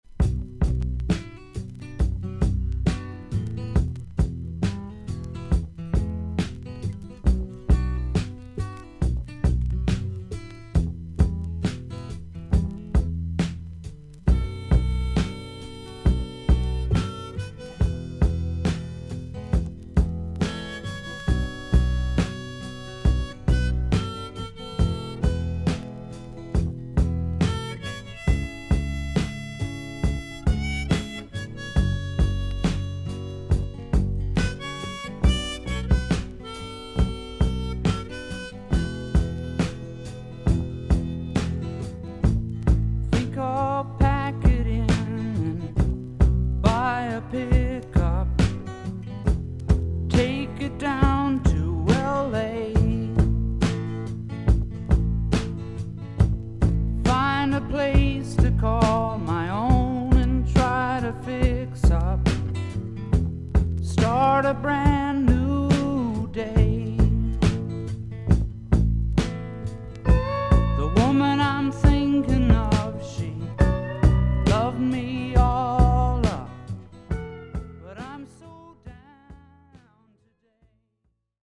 アメリカ盤 / 12インチ LP レコード / ステレオ盤
少々軽いパチノイズの箇所あり。少々サーフィス・ノイズあり。クリアな音です。